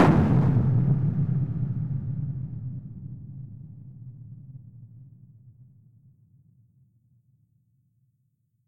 sounds_explosion_distant_02.ogg